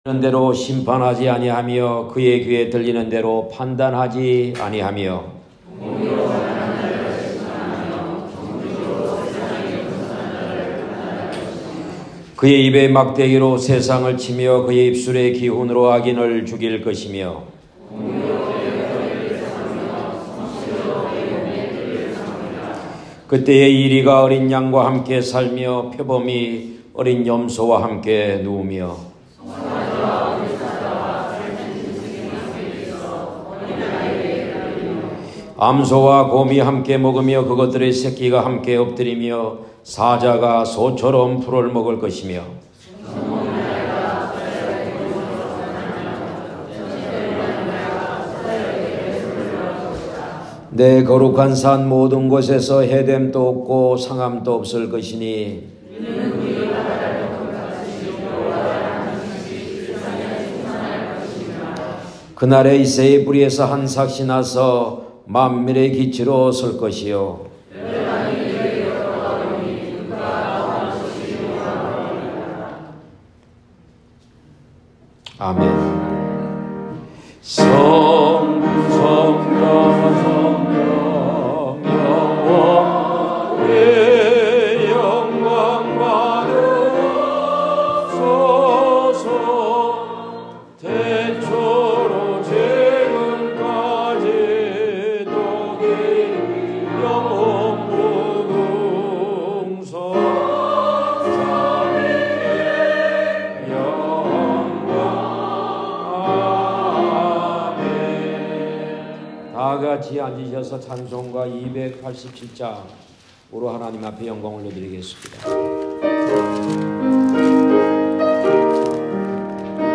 피아노